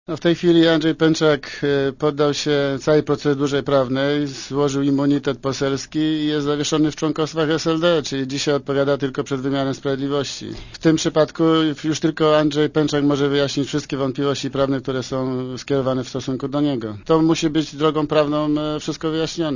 Sprawa przyjęcia łapówki przez Pęczaka musi być wyjaśniona przez organa sprawiedliwości, a będzie to ułatwione bo poseł zrzekł się immunitetu – powiedział w Radiu Zet sekretarz generalny SLD Marek Dyduch.
* Mówi Marek Dyduch*